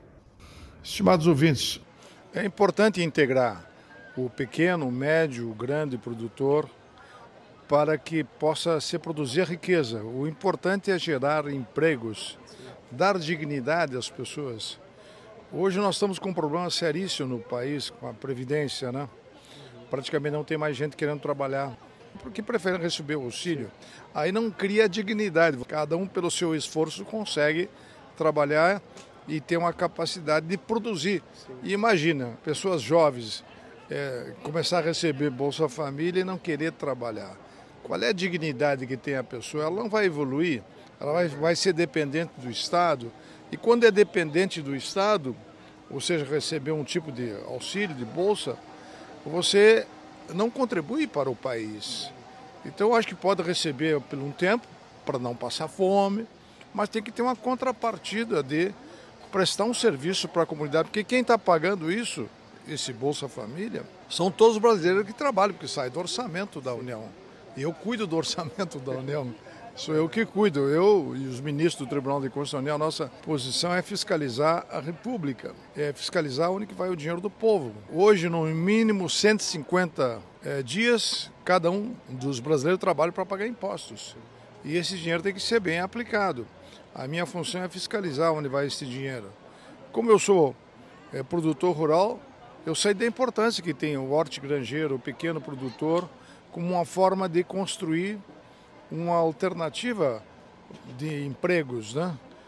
Comentário de Augusto Nardes, ministro do TCU.